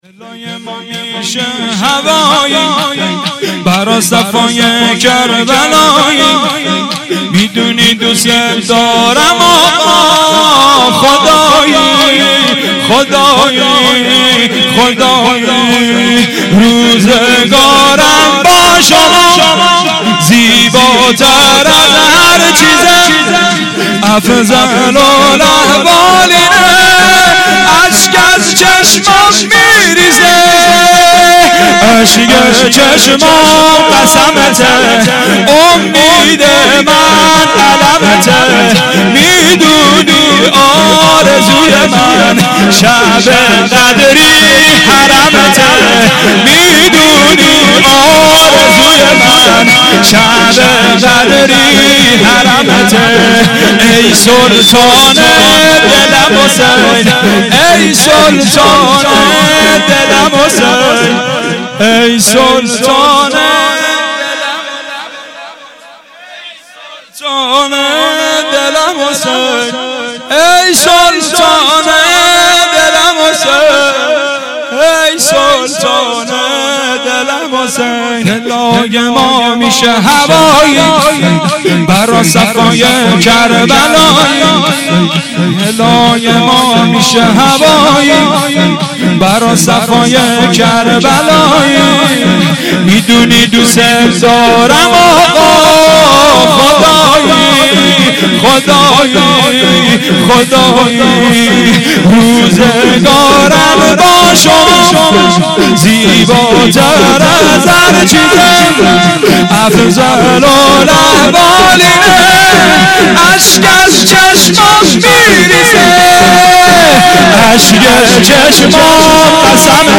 مداحی
در شب اول محرم 96